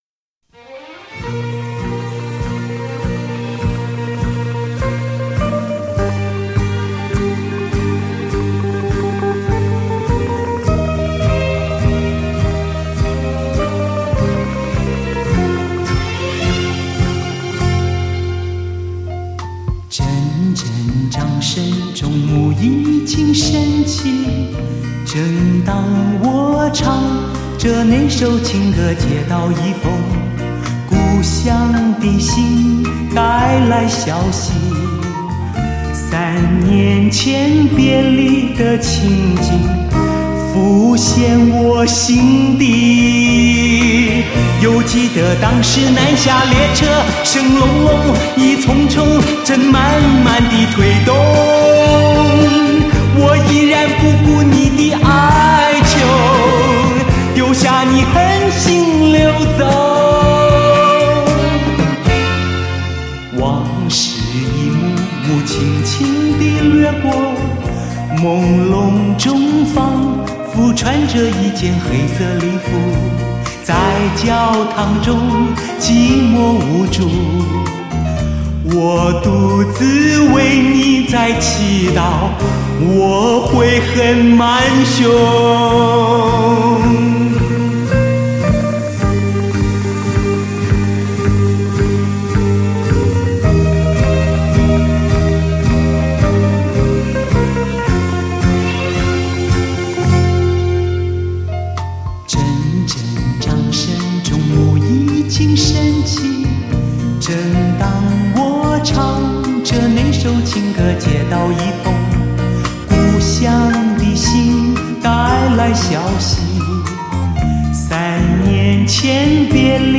它运用了一套复杂的编码技术，大大减低了失真，更具临场感，使音乐“原汁原味”地体现出乐曲的神韵，是音乐发烧友的珍藏品。